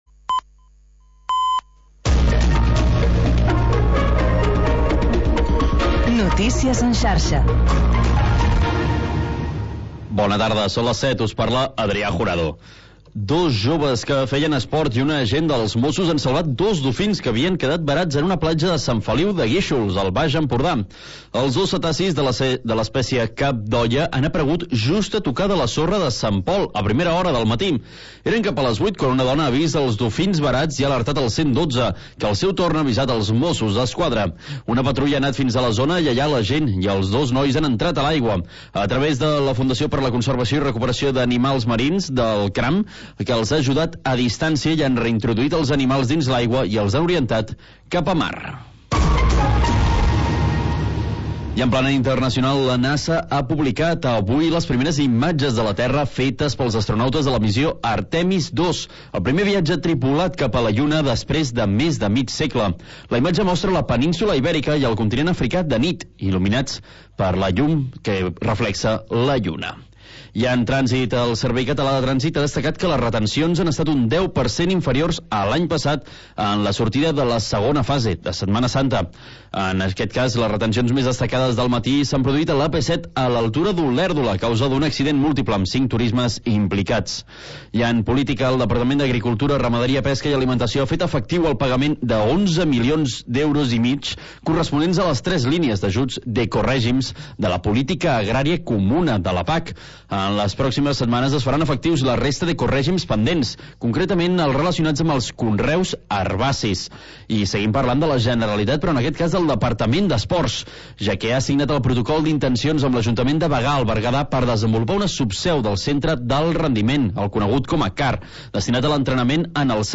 Informatiu territorial